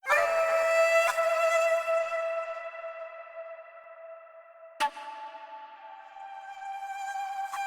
Stab